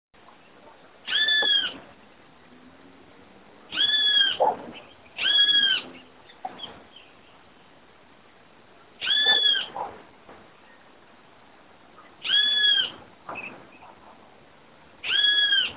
Bem-te-vi (Pitangus sulphuratus)
Varios individuos
Nome em Inglês: Great Kiskadee
Localidade ou área protegida: Reserva Ecológica Vicente López
Condição: Selvagem
Certeza: Observado, Gravado Vocal
Benteveo-Comun.mp3